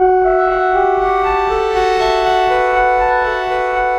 Flying Lead.wav